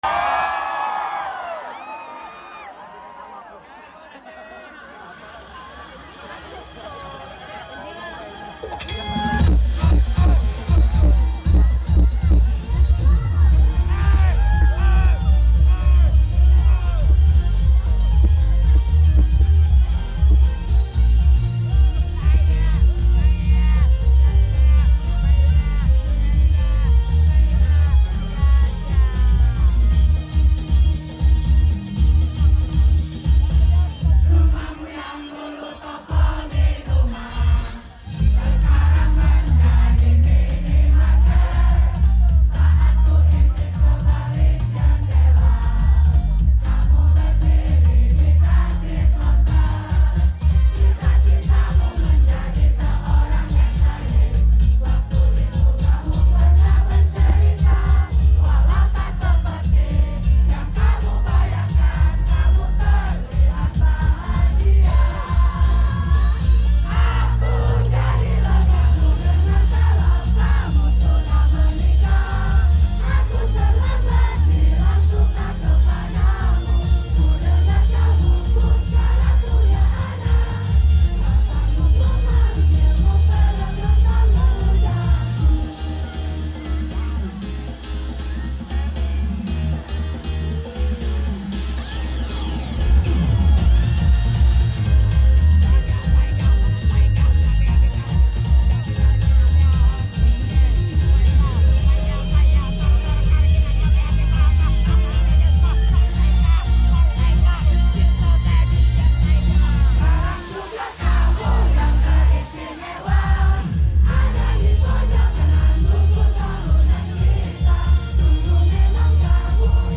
FANCAM